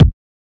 Kicks